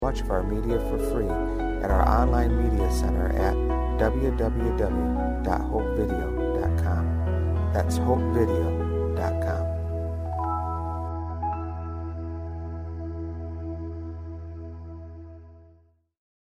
Tags: Sermon Preaching Jesus Bible God